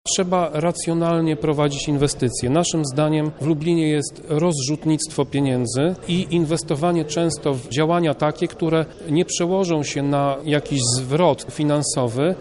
Inaczej patrzy na to Tomasz Pitucha, radny z ramienia PIS
sesja-rady-miasta2.mp3